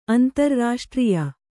♪ antarrāṣtrīya